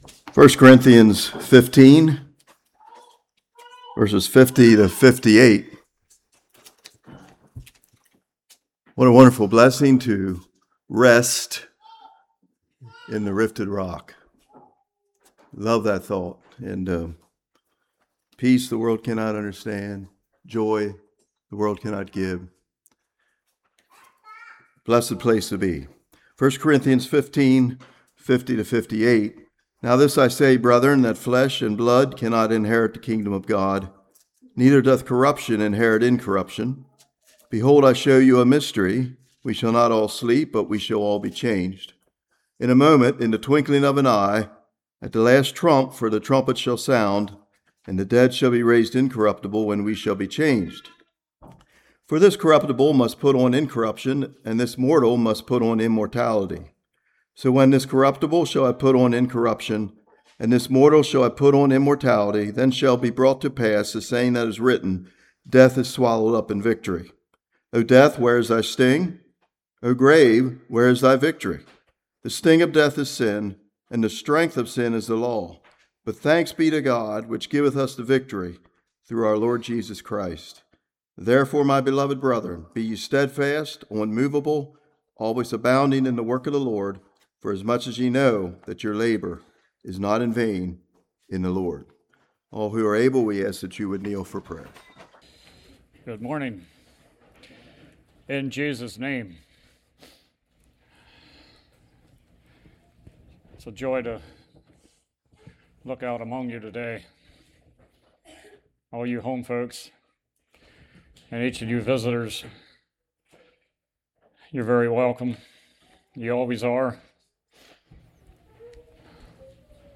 1 Corinthians 15:50-58 Service Type: Morning Our physical flesh and blood bodies cannot enter into heaven